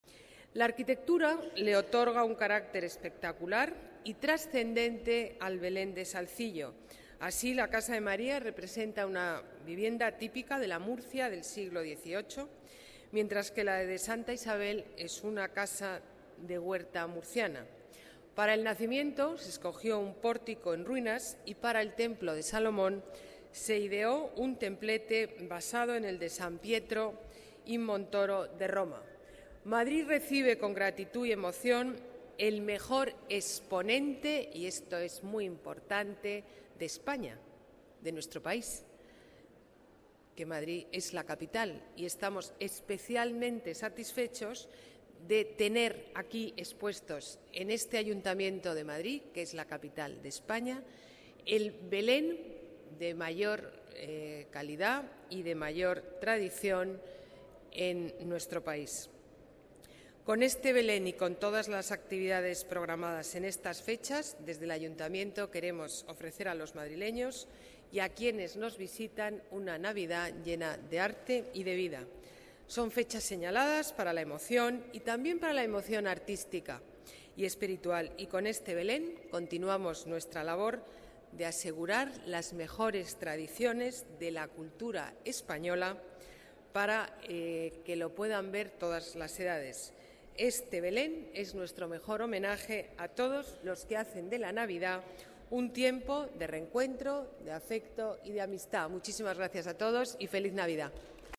Más archivos multimedia Ana Botella destaca alguna de las particularidades del Belén y reseña algunas de sus piezas más destacables Vídeo acto inauguración Belén de Salzillo Más documentos Discurso de la Alcaldesa en la inauguración del Belén de Salzillo